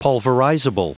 Prononciation du mot pulverizable en anglais (fichier audio)
Prononciation du mot : pulverizable